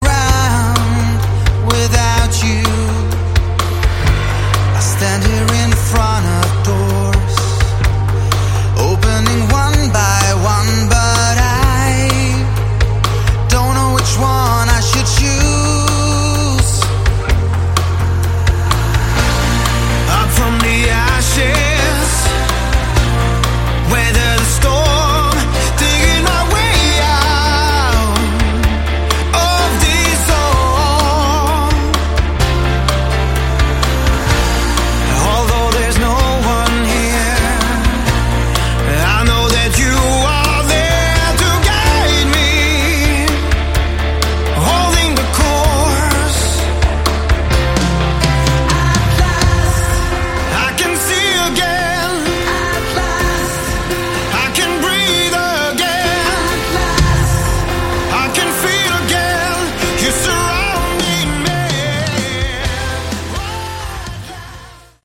Category: AOR
keyboards, vocals
guitar, bass
drums